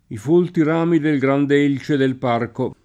i f1lti r#mi del gr#nde %l©e del p#rko] (Calvino) — elce o elice, in origine, continuaz. pop. del s. f. lat. ilex con i- lungo, genit. ilicis (propr., della tarda variante elix con e- lunga, genit. elicis), in parallelo con l’allòtropo dòtto ilice; ma confinata per tempo, a sua volta, nell’uso lett. di fronte al prevalere della voce leccio, continuaz. pop. dell’agg. lat. iliceus (propr. «di leccio») — sim. i top. Elce (Abr.), Elci (Lazio), Elice (Abr.), Villa Elce (id.), Pieve a Elici (Tosc.), Fontanelice (E.-R.), e i cogn. D’Elci, Dell’Elce